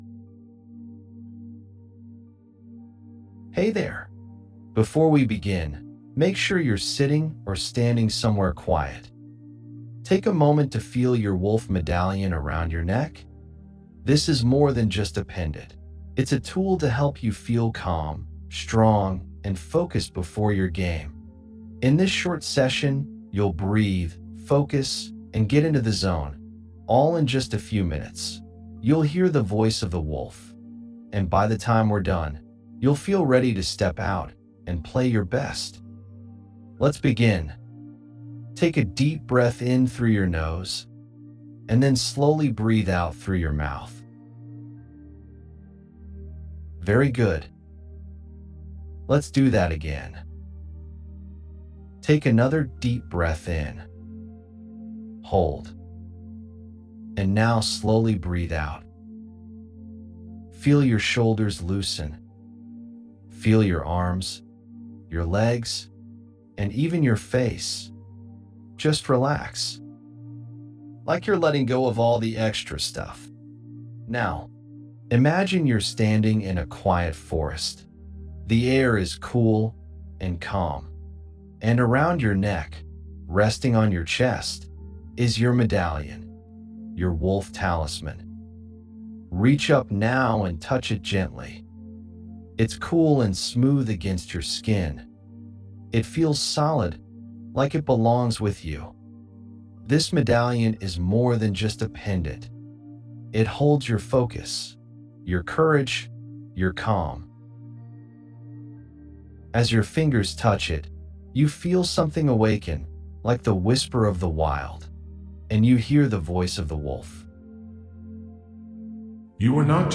5-Minute Guided Audio: A quick mindfulness session that helps your child focus, reduce anxiety, and enhance their performance with ease.
soccer_mindfulness_demo.mp3